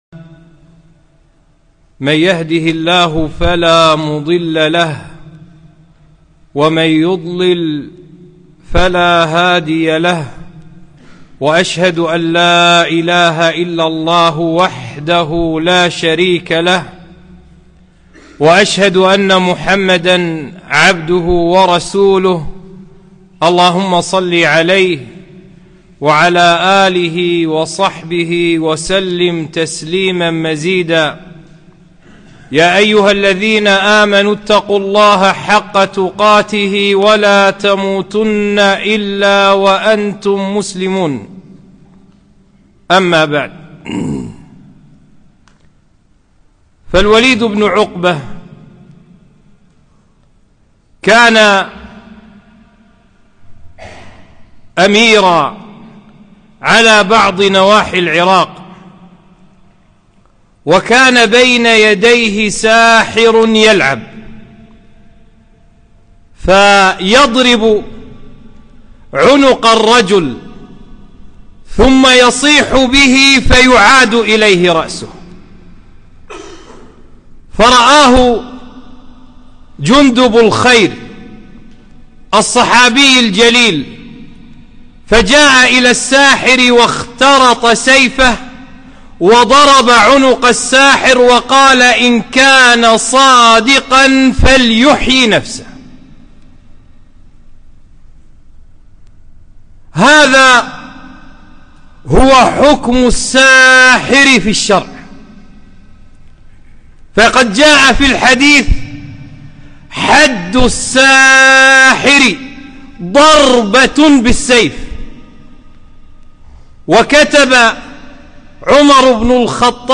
خطبة - ( مهمات في السحر )